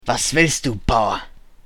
Deutsche Sprecher (m)
Ansonsten spreche ich alle menschlichen Rassen